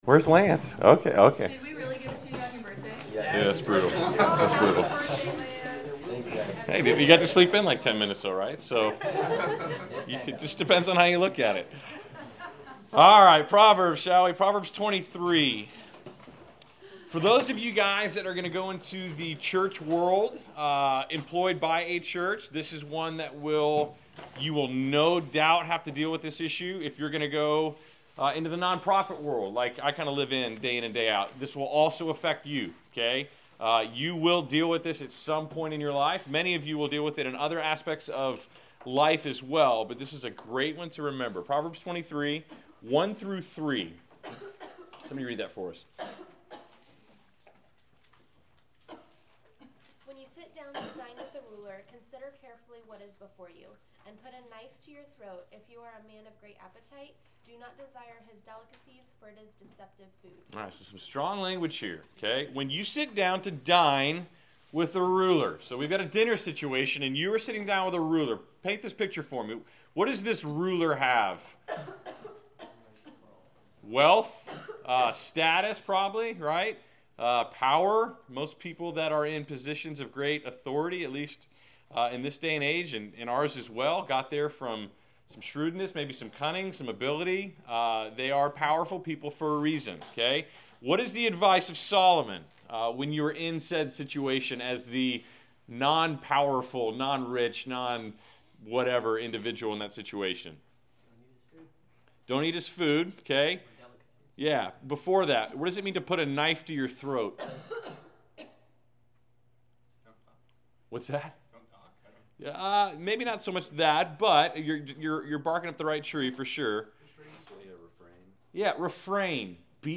Class Session Audio September 19